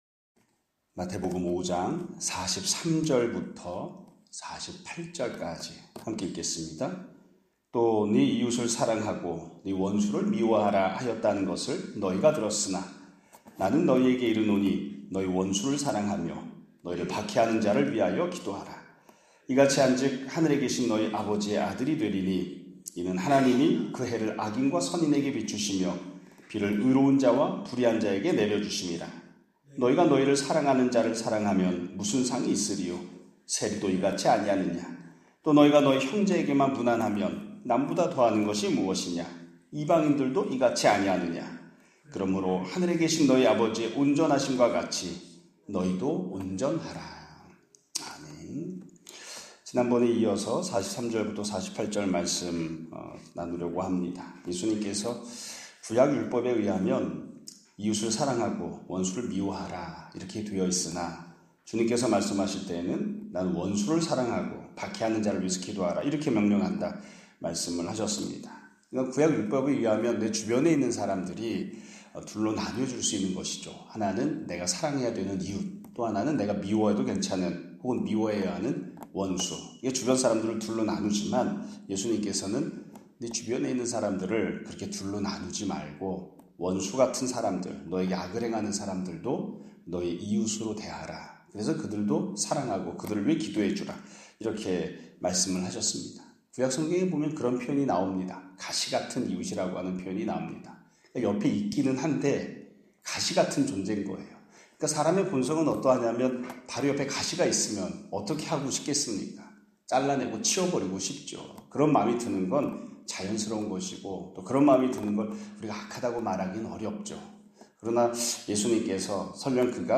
2025년 6월 4일(수 요일) <아침예배> 설교입니다.